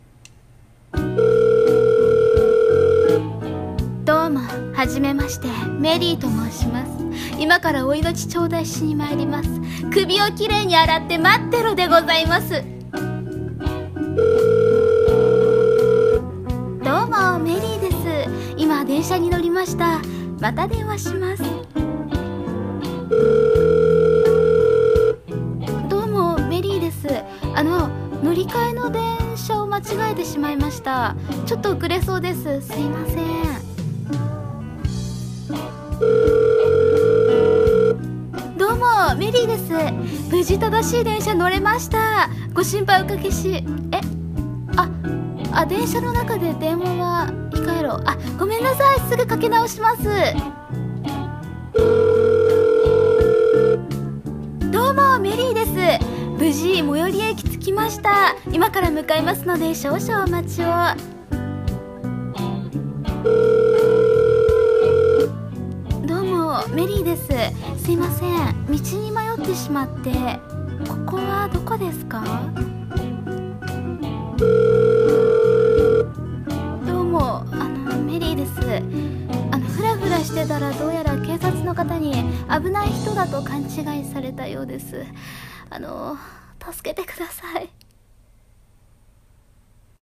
【ギャグ声劇台本】どうも、メリーです。